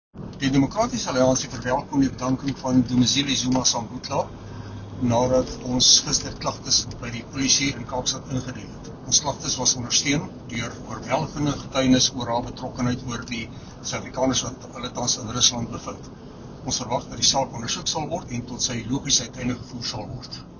Afrikaans soundbite by Chris Hattingh MP.